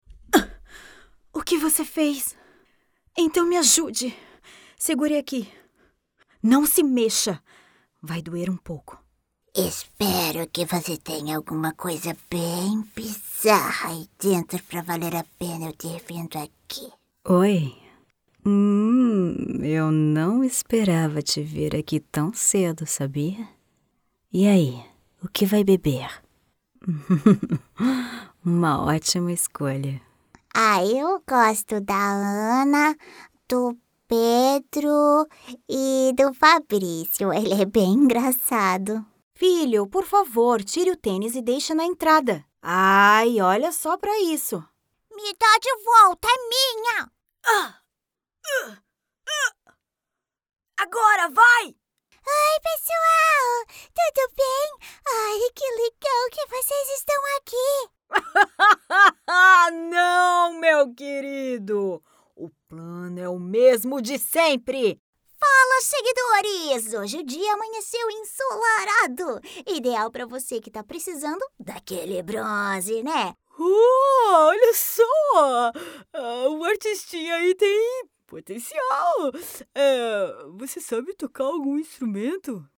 Portuguese Female No.1
Company Promo